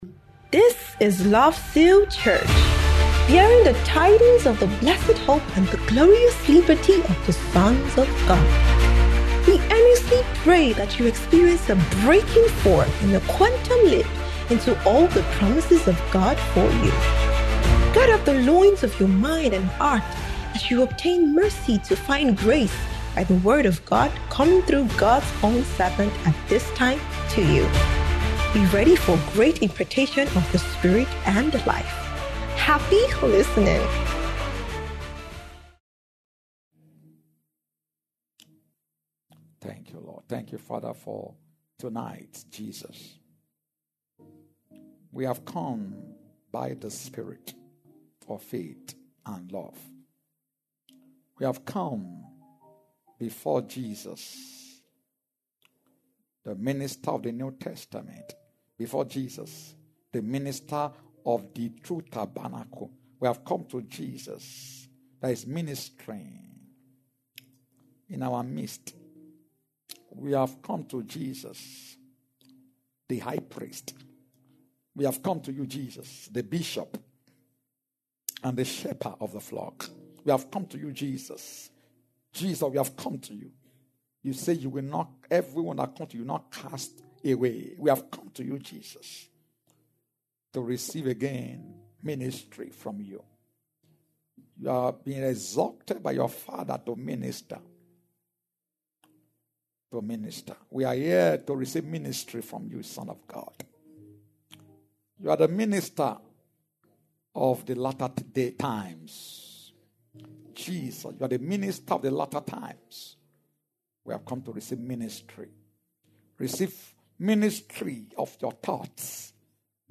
New Year's Eve Prophetic Crossover Service & Thanksgiving